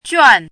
怎么读
juàn
juan4.mp3